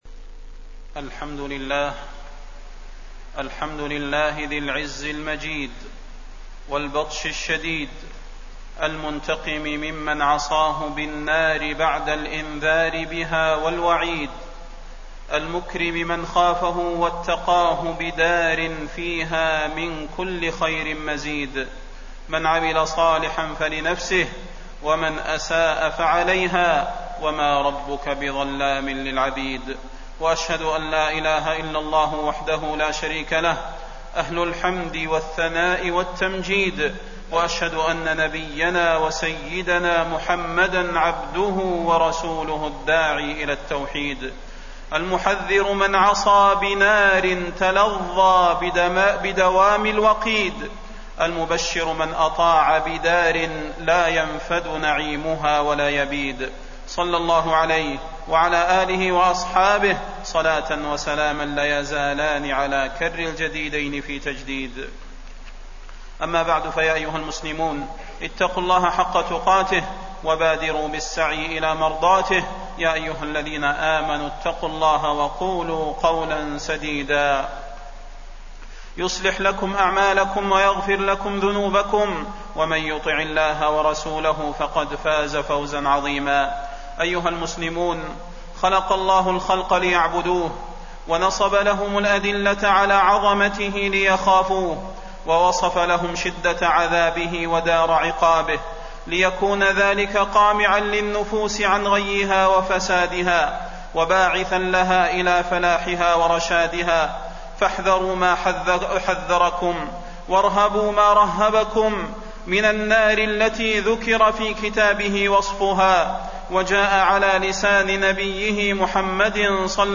تاريخ النشر ١١ شعبان ١٤٣١ هـ المكان: المسجد النبوي الشيخ: فضيلة الشيخ د. صلاح بن محمد البدير فضيلة الشيخ د. صلاح بن محمد البدير أنذرتكم النار